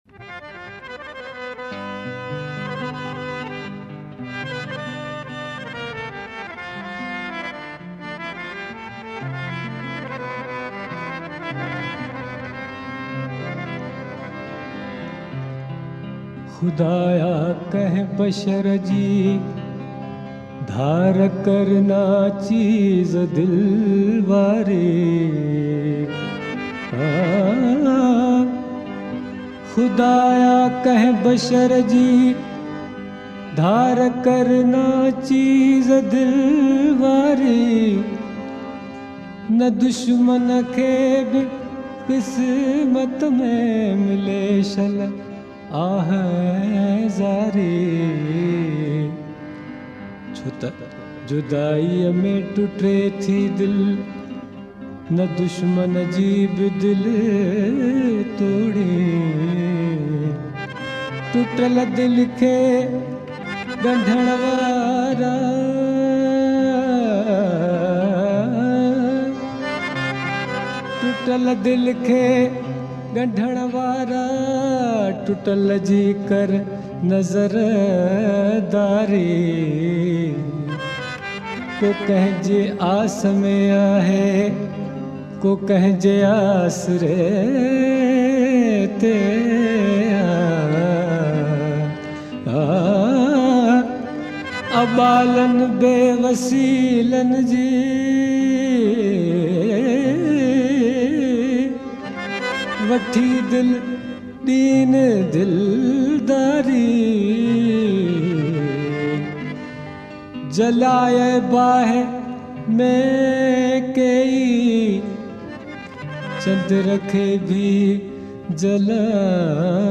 soothing voice